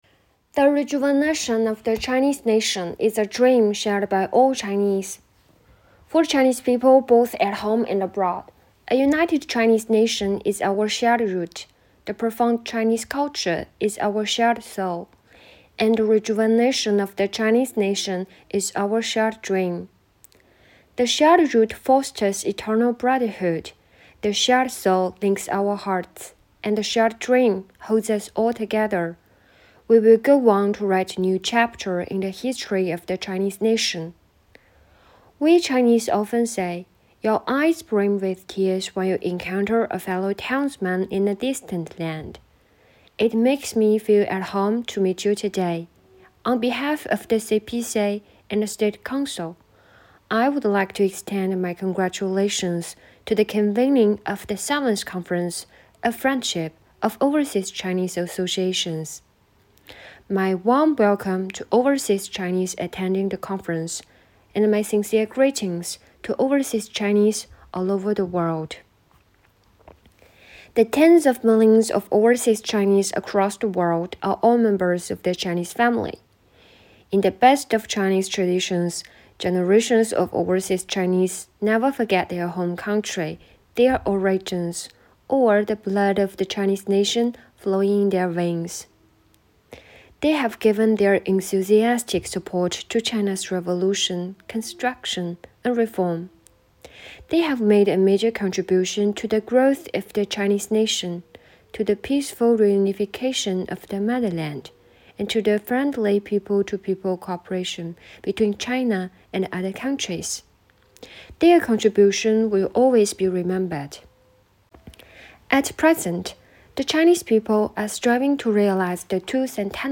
活动简介：“语你E起”学习“治国理政”朗诵系列活动是外国语学院英语教师第一党支部推出的具有“双语特色”的创新学习活动。